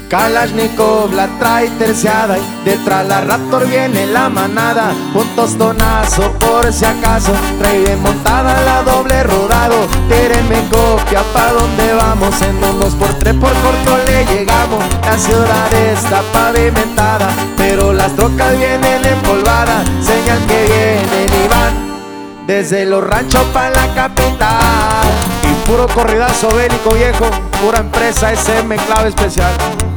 # Regional Mexican